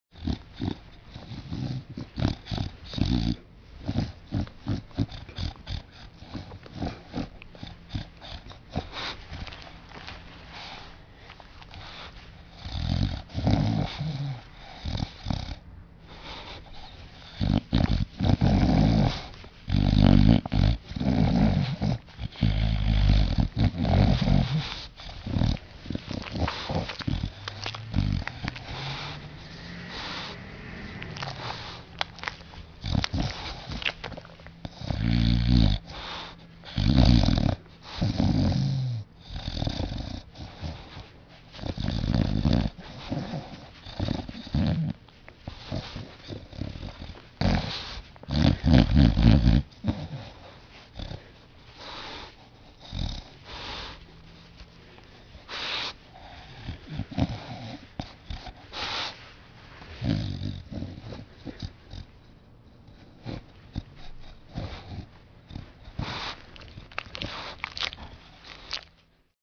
Звук фыркающего бульдога во сне собачий храп